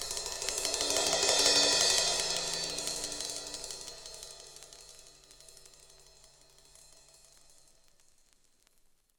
Closed Hats
RIDES_CYMB.wav